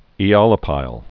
(ē-ŏlə-pīl)